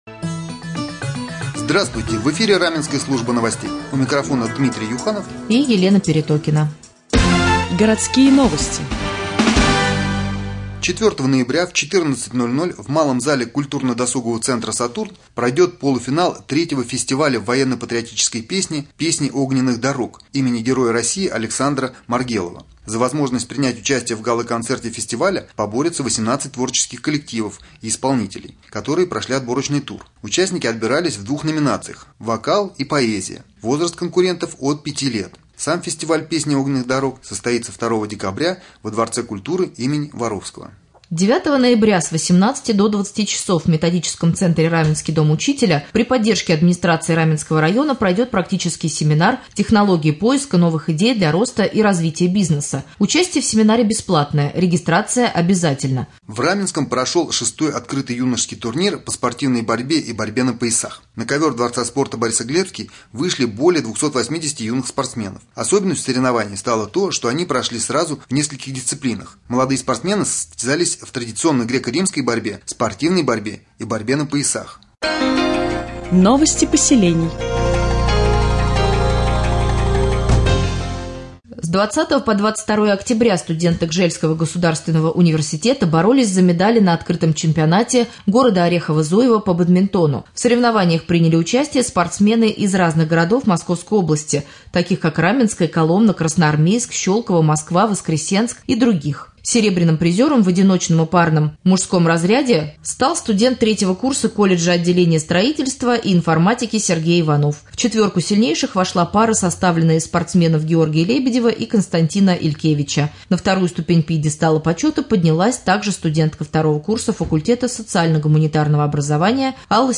О семинаре для представителей бизнеса и фестивале патриотической песни слушайте в новостном блоке Раменского радио